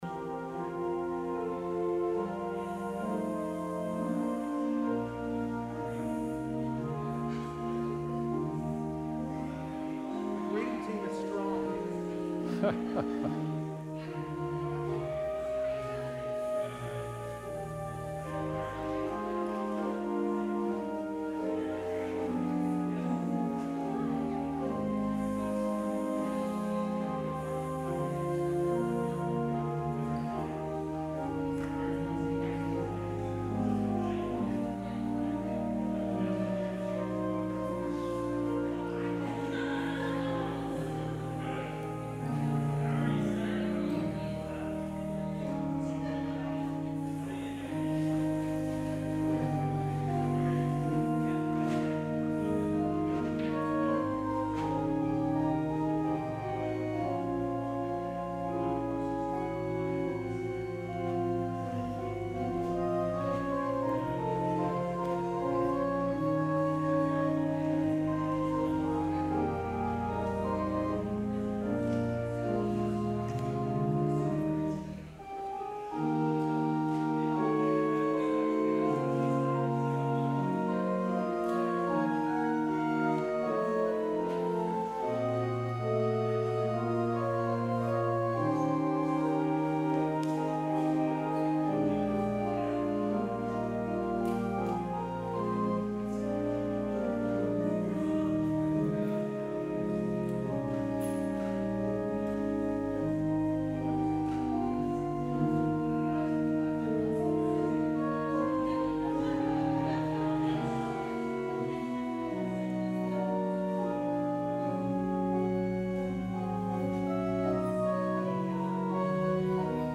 June 16, 2019 Service
Traditional Sermon